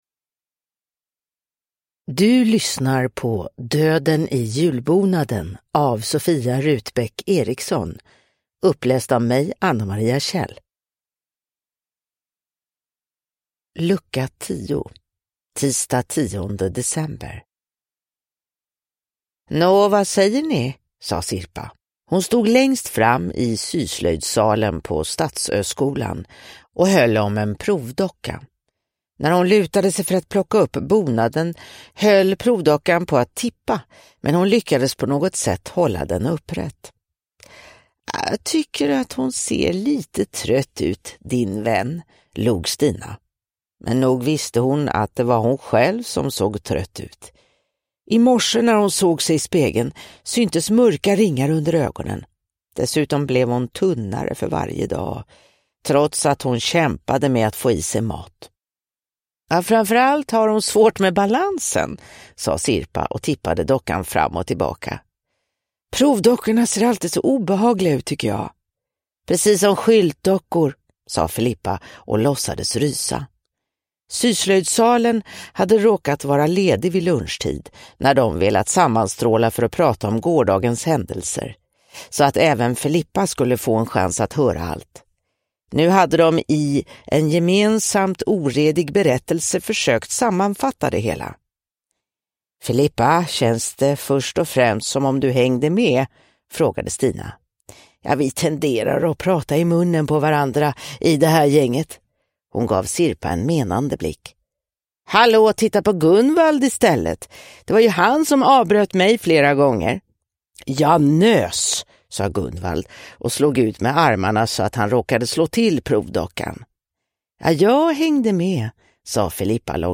Döden i julbonaden: Lucka 10 – Ljudbok